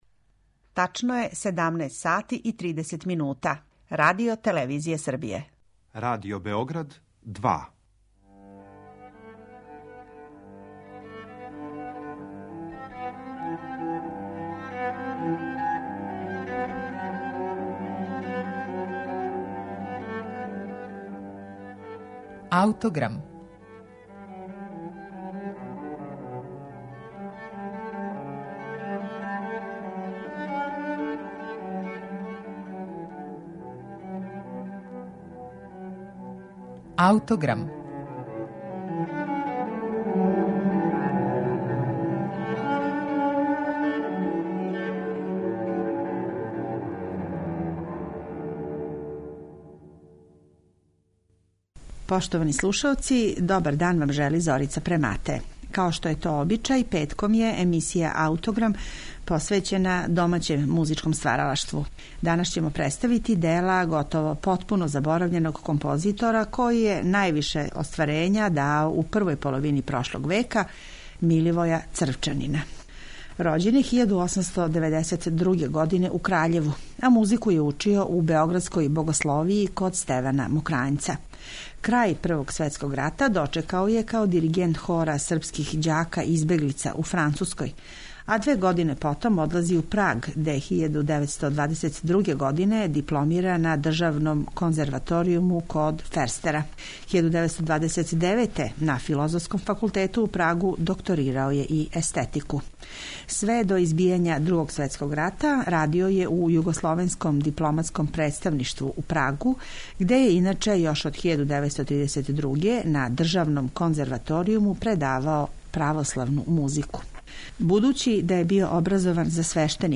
Слушаћете архивски снимак композиције једног нашег заборављеног аутора
Дело изводи Оркестар Словеначке филхармоније, којим диригује Само Хубад.